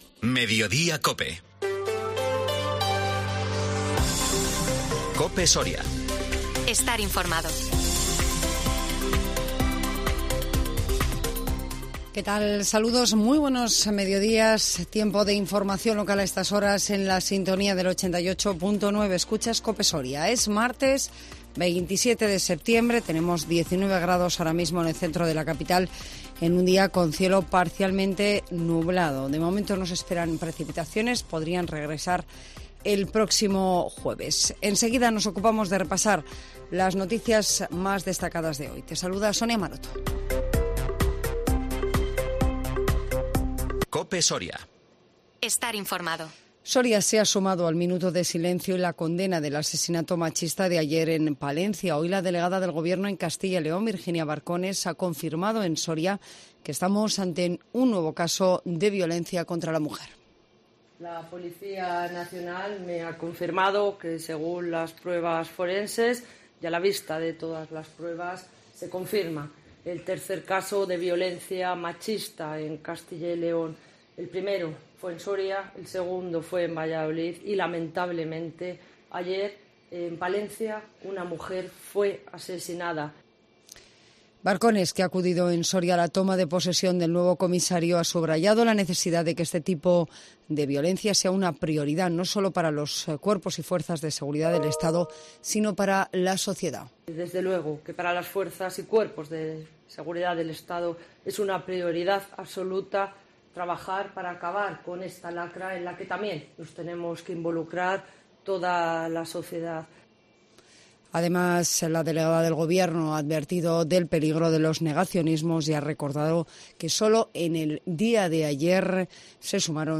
INFORMATIVO MEDIODÍA COPE SORIA 27 SEPTIEMBRE 2022